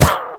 Minecraft Version Minecraft Version 25w18a Latest Release | Latest Snapshot 25w18a / assets / minecraft / sounds / entity / witch / hurt2.ogg Compare With Compare With Latest Release | Latest Snapshot
hurt2.ogg